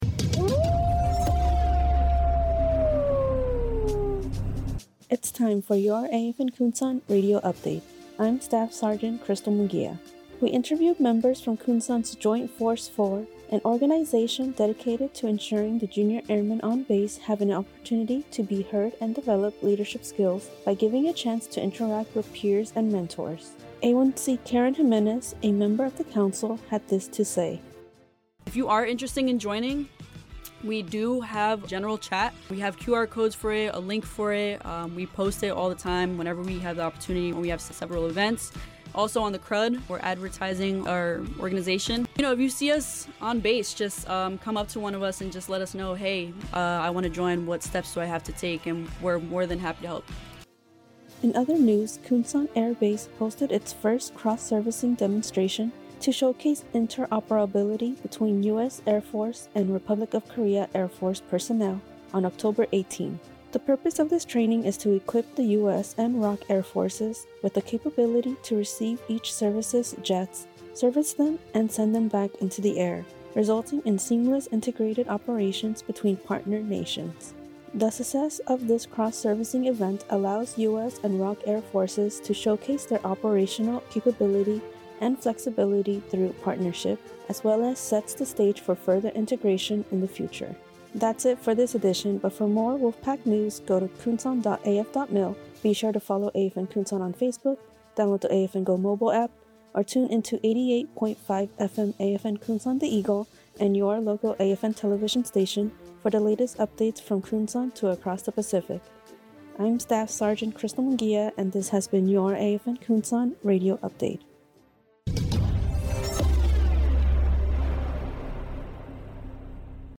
This AFN Kunsan Radio Update covers an interview with members of Kunsan's Joint Force Four, and the base's first cross-servicing demonstration to showcase interoperability between U.S. Air Force and Republic of Korea Air Force personnel.